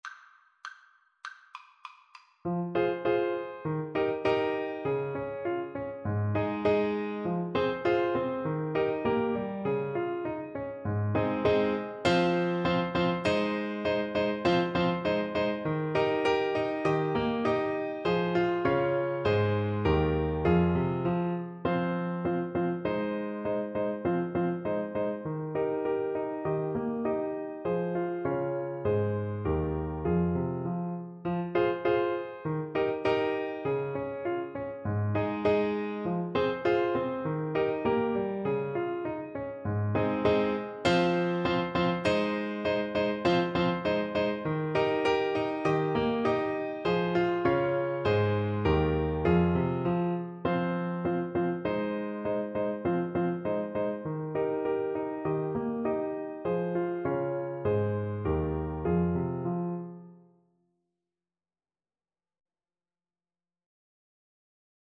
Alto Saxophone
2/2 (View more 2/2 Music)
= 100 A